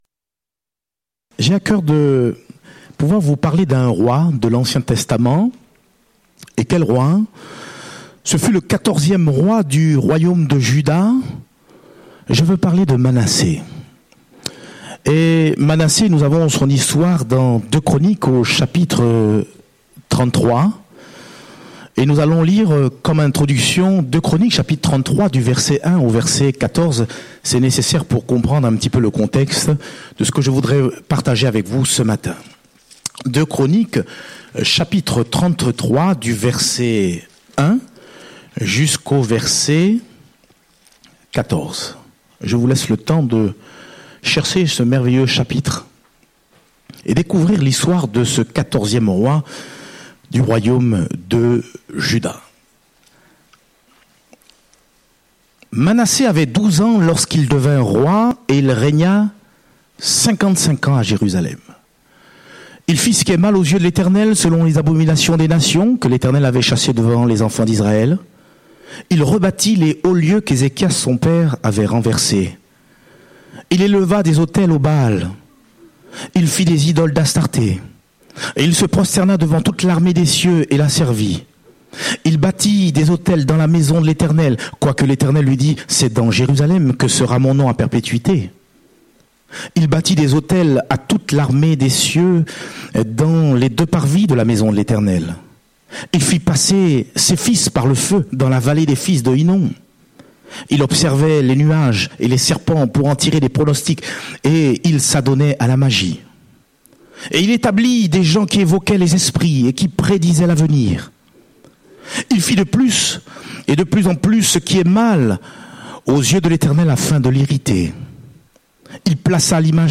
Date : 15 juillet 2018 (Culte Dominical)